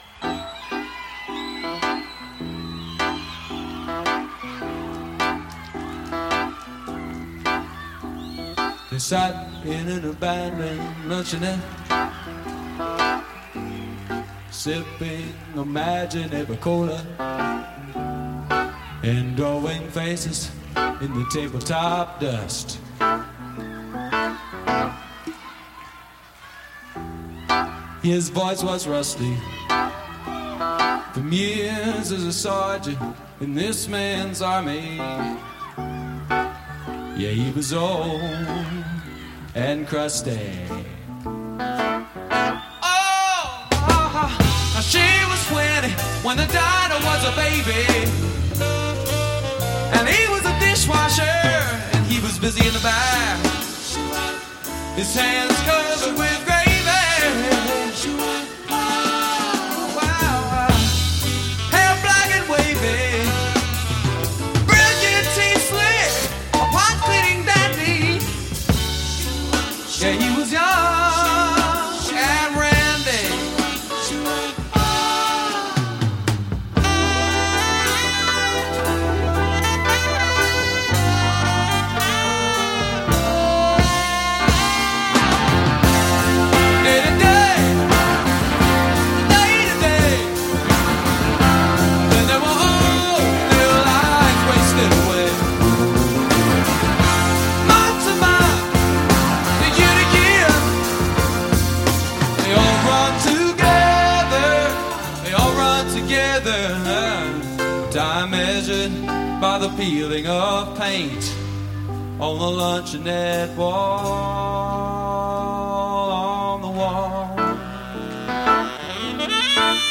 Live at Hersheypark Arena, Philadelphia, PA - December 1977